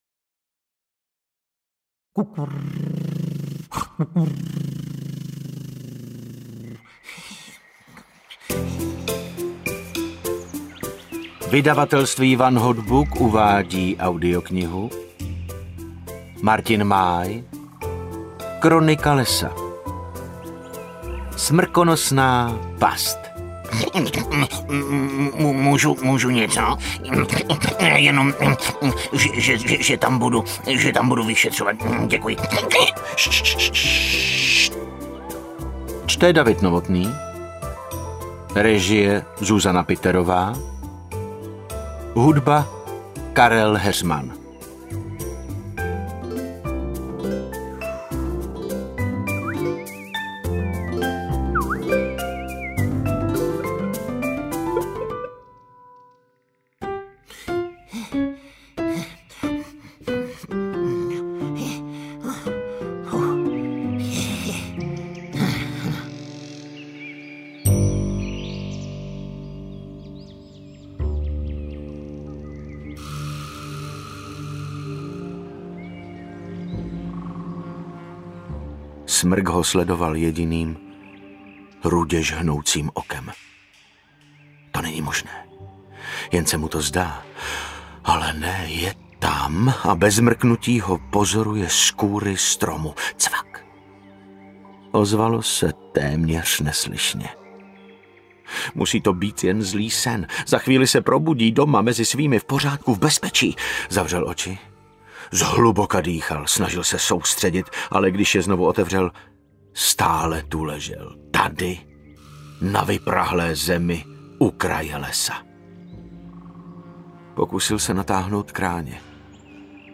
Kronika lesa 2: Smrkonosná past audiokniha
Ukázka z knihy
• InterpretDavid Novotný